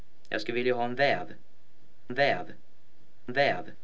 Keskiruotsissa monet pitkät vokaalit diftongoituvat.
Kaikki ne ääntyvät väljentyen eli aueten äänteen loppua kohti.
Kuulethan diftongoitumisen?